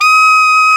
Index of /90_sSampleCDs/Roland L-CDX-03 Disk 1/SAX_Alto Short/SAX_A.mf 414 Sh
SAX A.MF E0R.wav